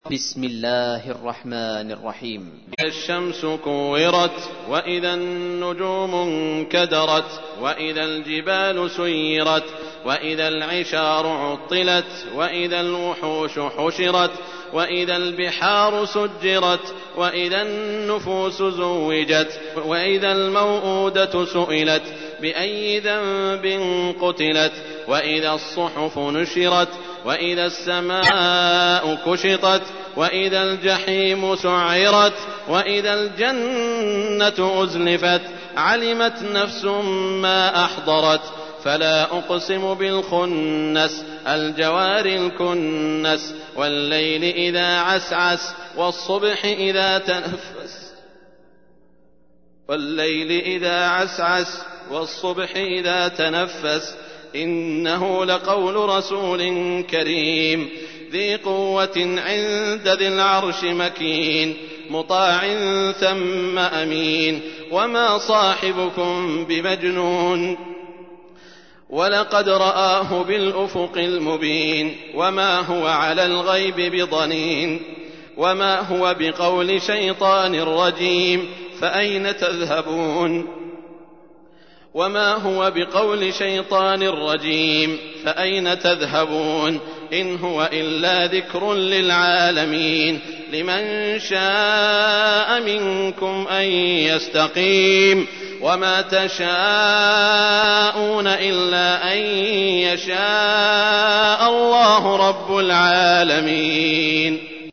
تحميل : 81. سورة التكوير / القارئ سعود الشريم / القرآن الكريم / موقع يا حسين